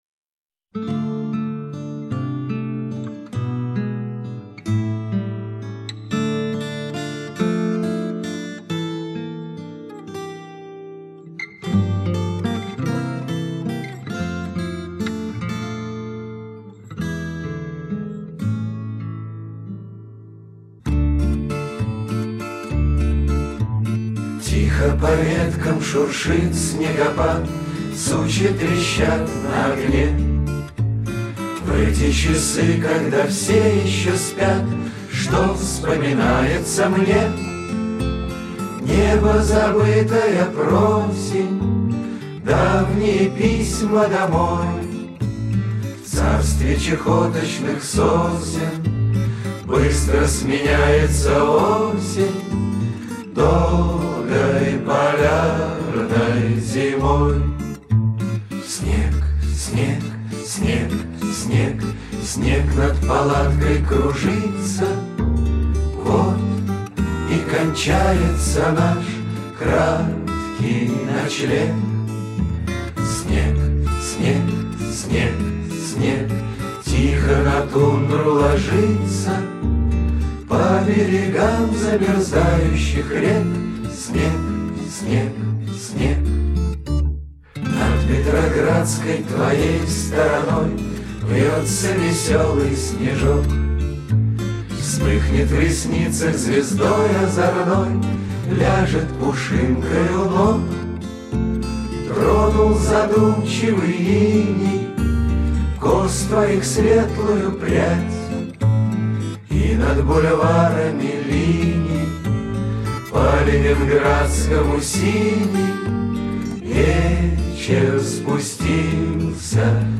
Авторское исполнение